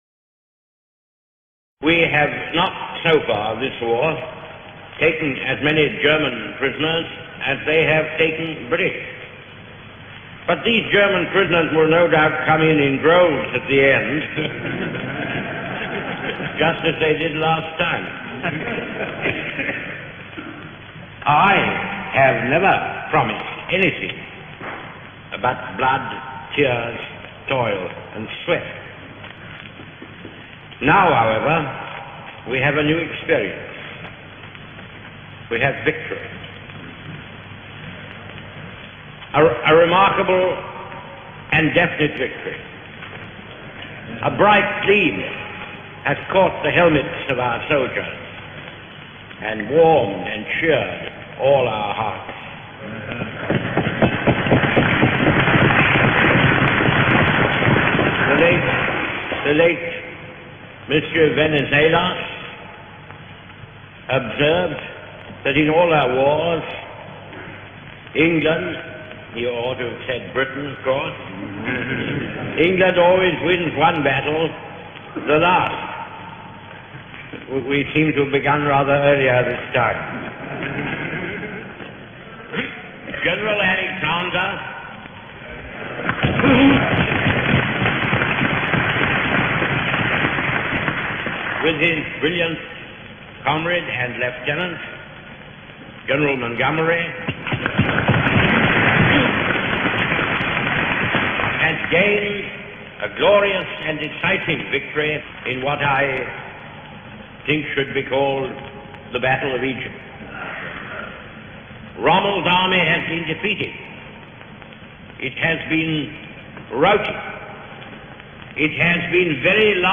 The Famous Wartime Speeches of Winston Churchill